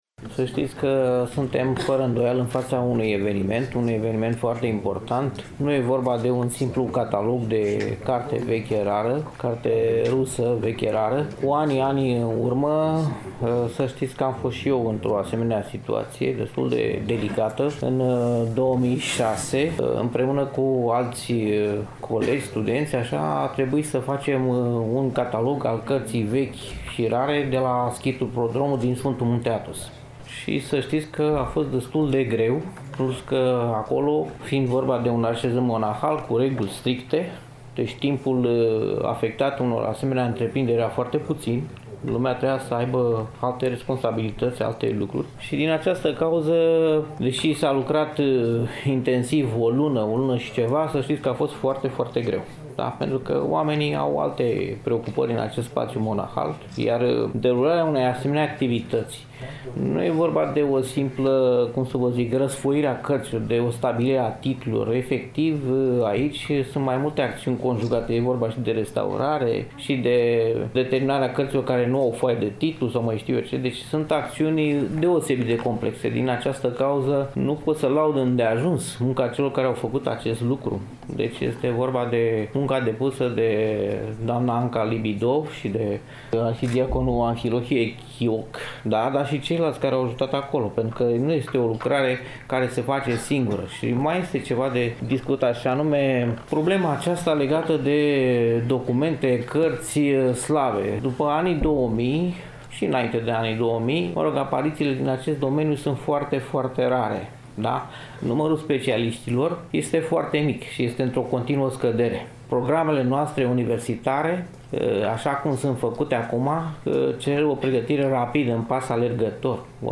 Evenimentul a avut loc, nu demult, în Amfiteatrul „I. H. Rădulescu” al Bibliotecii Academiei Române, București.